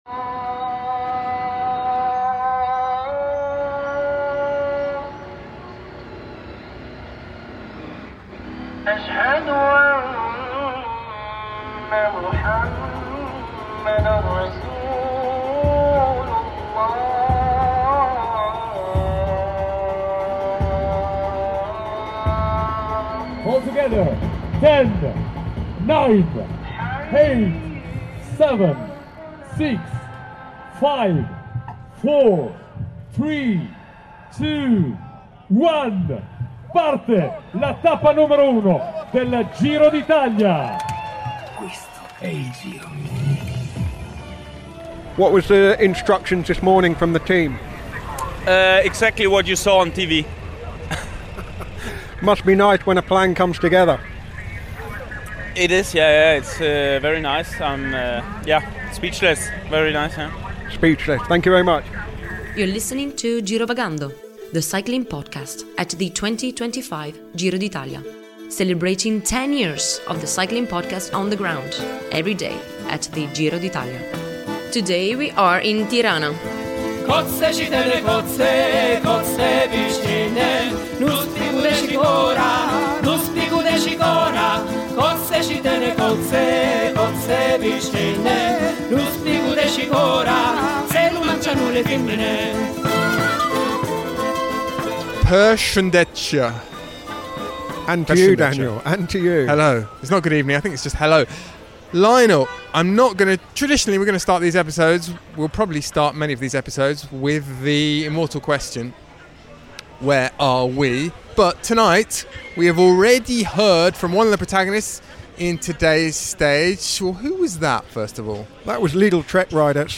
Join us for daily coverage of the Giro d’Italia recorded on the road as the race makes its way from Albania to Rome. Our daily coverage features race analysis, interviews and daily postcards from Italy, plus our regular check-in with Tudor Pro Cycling’s Larry Warbasse.